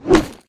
inv_drop.ogg